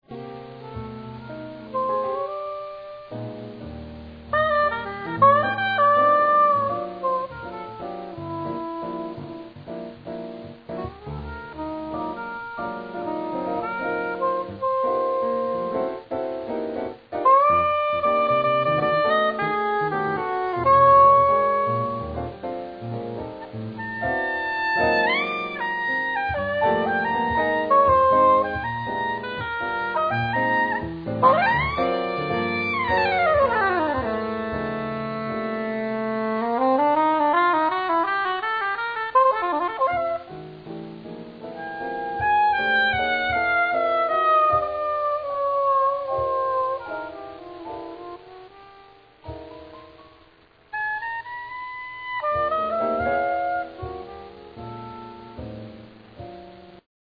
duets with pianists